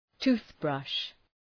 Προφορά
{‘tu:ɵbrʌʃ}